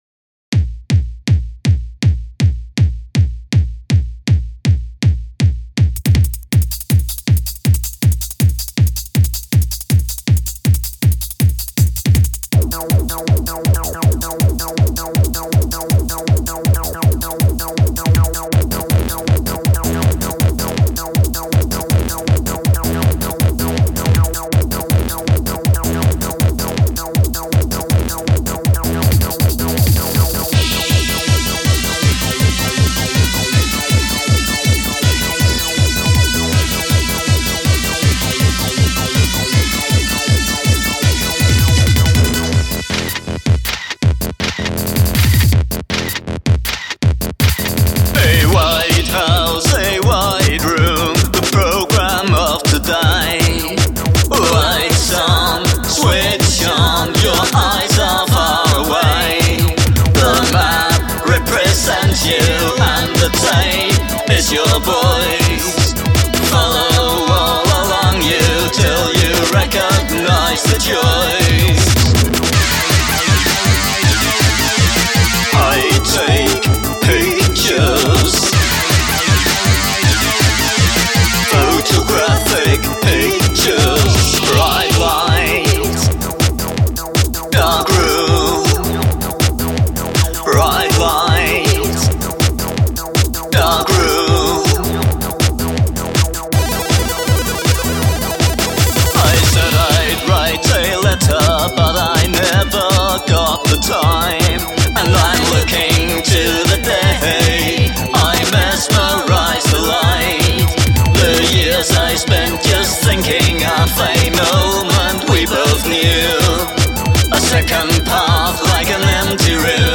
All instruments and vocals